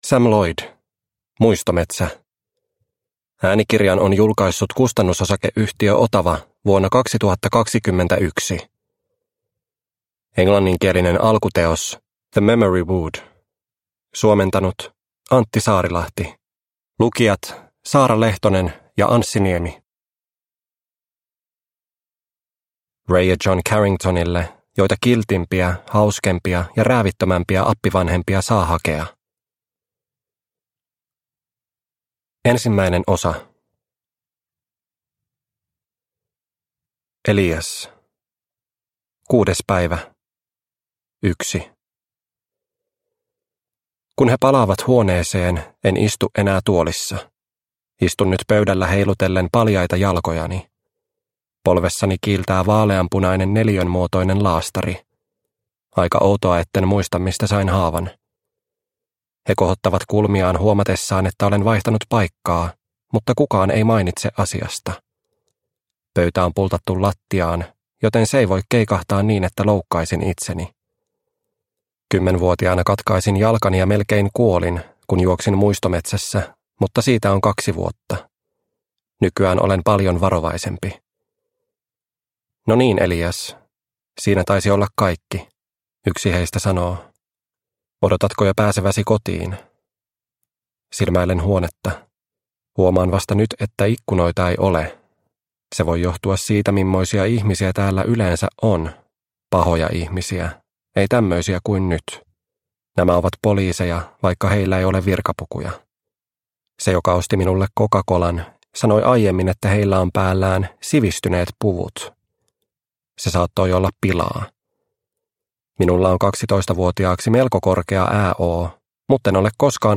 Muistometsä – Ljudbok – Laddas ner